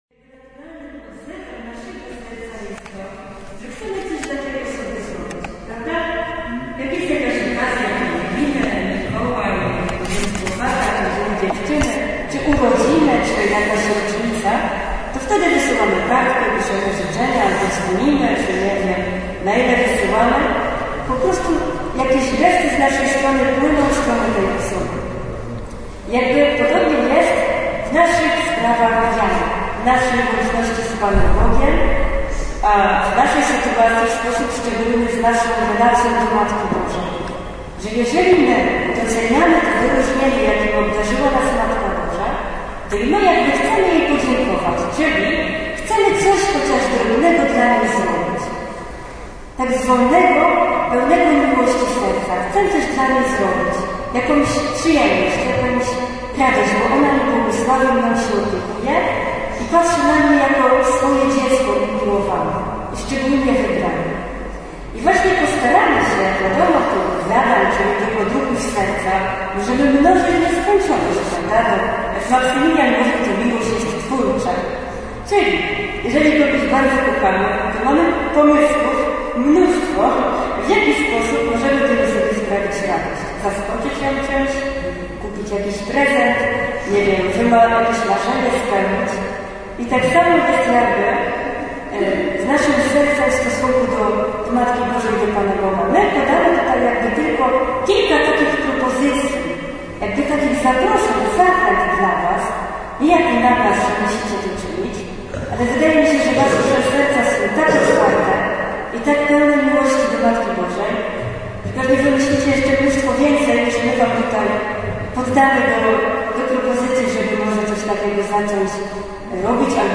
Zachęcamy do odwiedzenia galerii, oraz do posłuchania dźwięku, jakiego udało się nam zarejestrować w Dąbrówce.